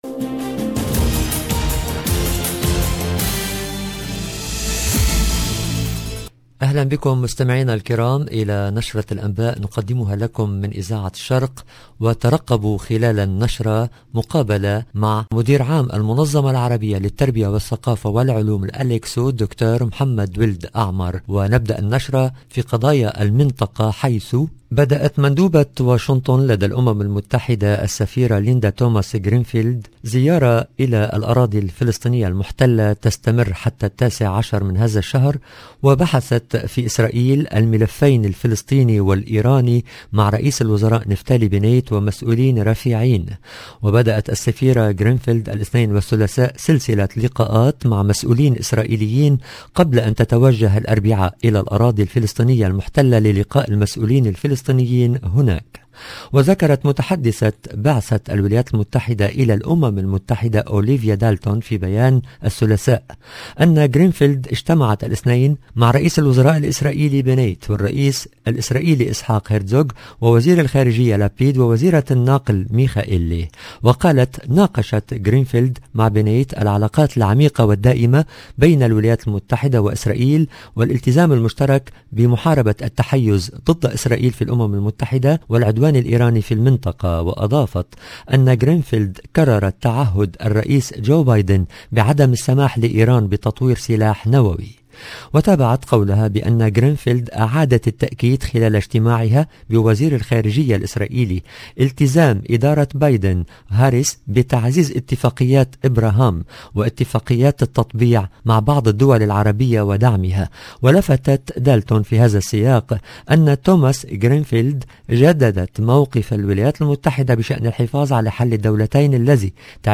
LE JOURNAL DU SOIR EN LANGUE ARABE DU 16/12/21